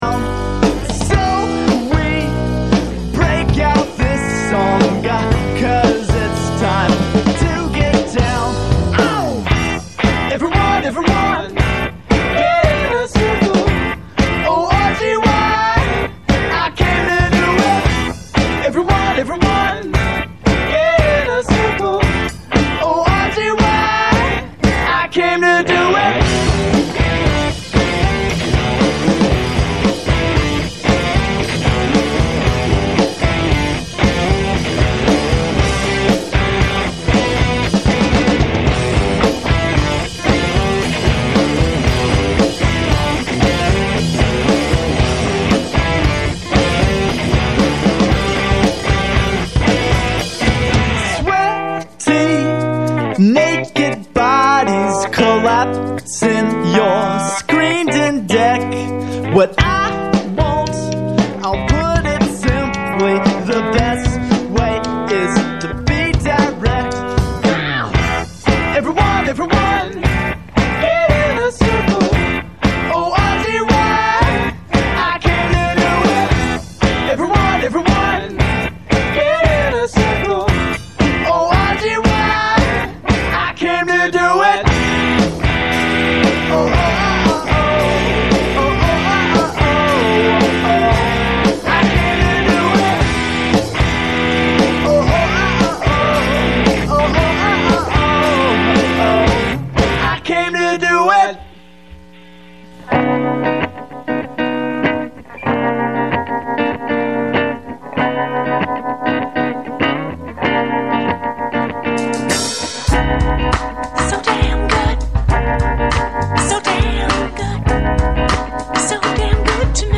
radio
musical guests